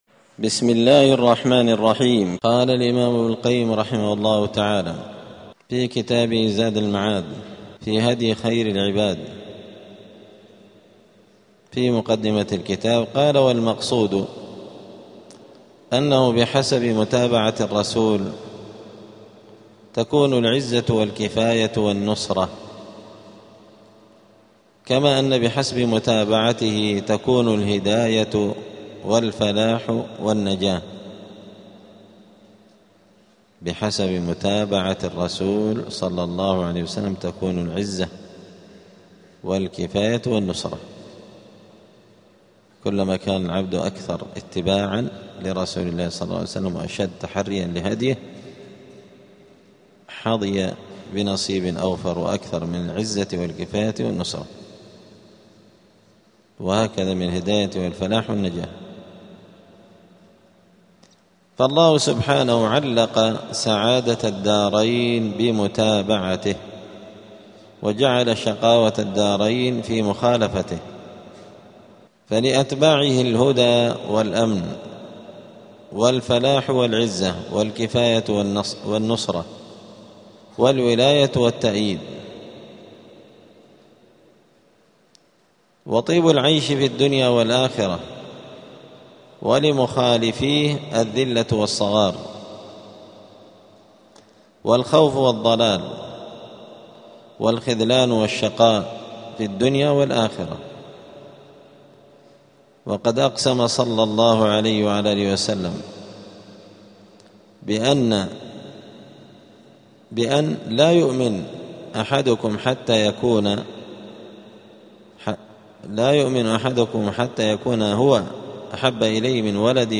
*الدرس الثالث (3) {مقدمة المؤلف}.*